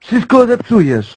Worms speechbanks
runaway.wav